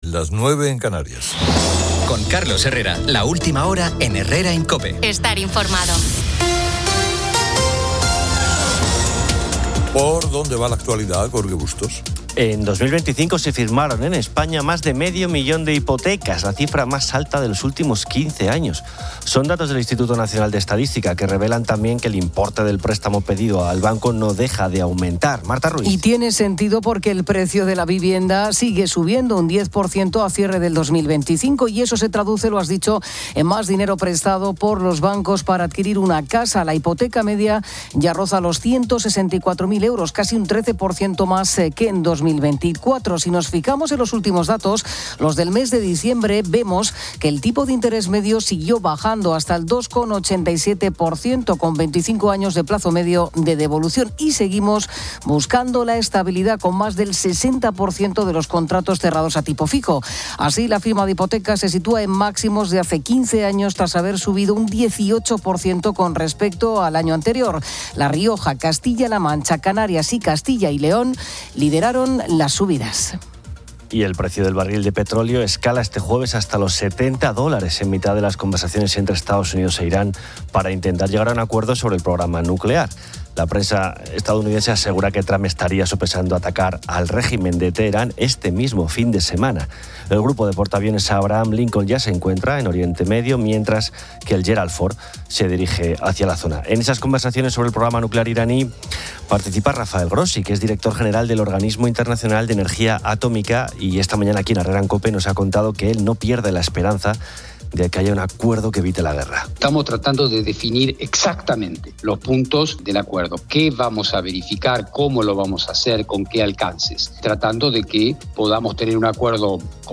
Un segmento del programa aborda deportes extremos. Oyentes y colaboradores comparten experiencias (paracaidismo, parapente, parkour), discutiendo riesgos, la búsqueda de adrenalina y el equilibrio vital.